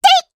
Taily-Vox_Attack2_jp.wav